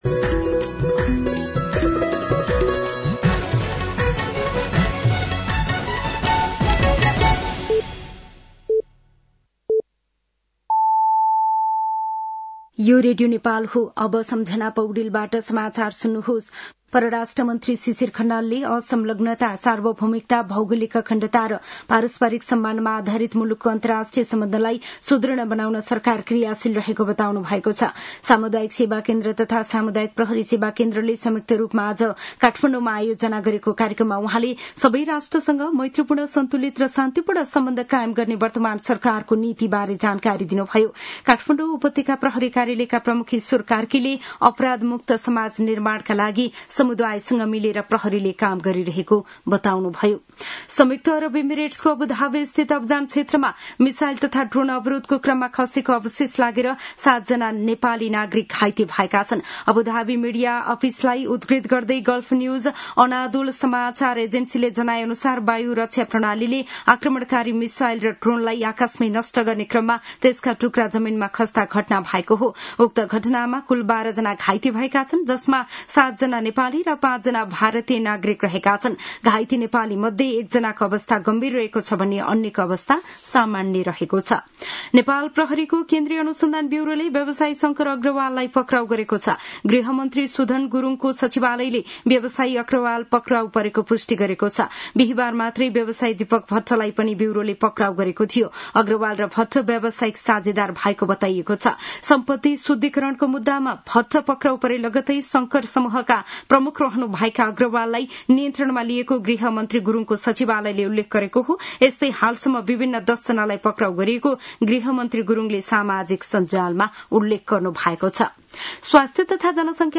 साँझ ५ बजेको नेपाली समाचार : २१ चैत , २०८२